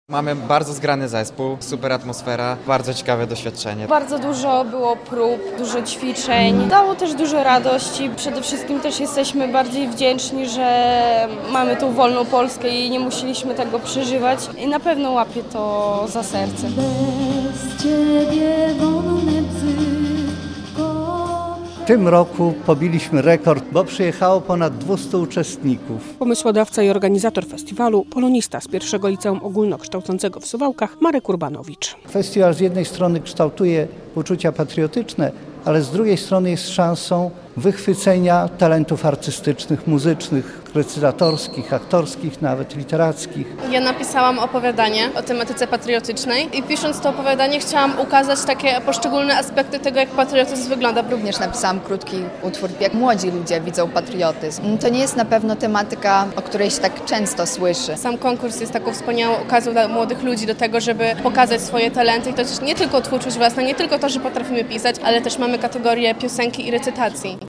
Wojewódzki Festiwal Piosenki i Poezji Patriotycznej - relacja